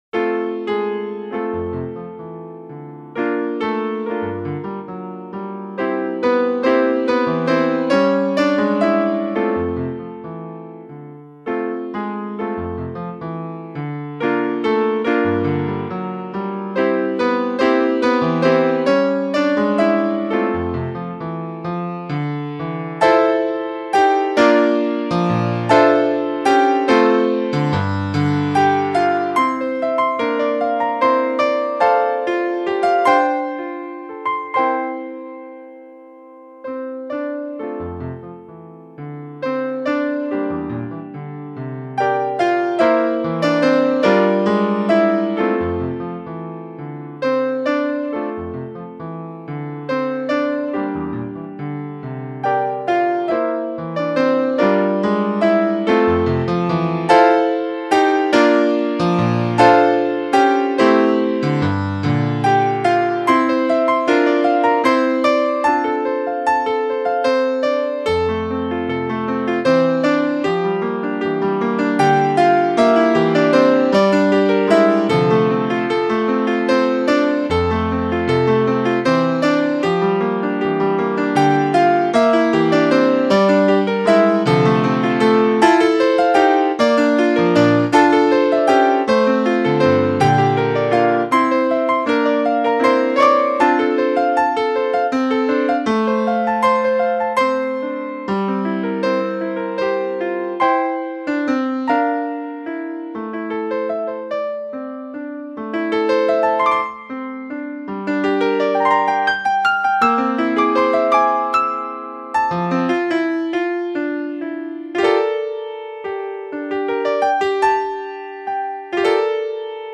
interpretada por su autor, es una zamba